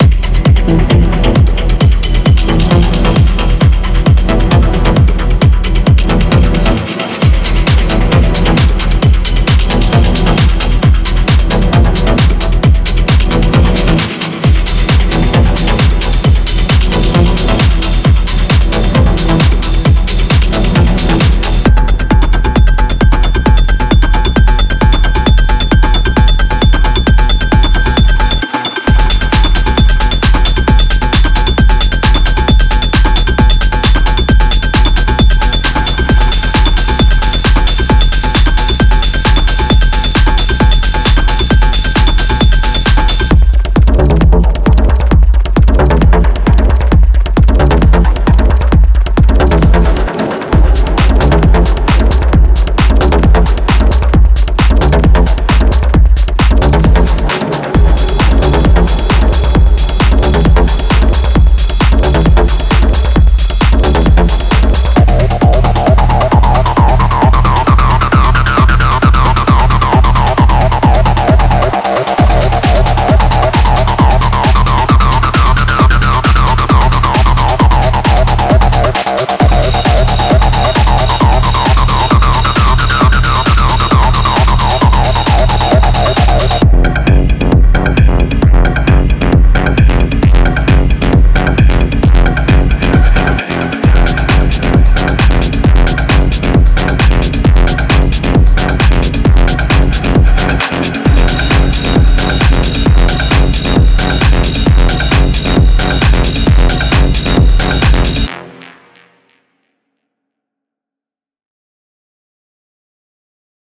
デモサウンドはコチラ↓
Genre:Techno
95 Drum loops (Full, Kick, Clap, Hihat, Perc, Ride)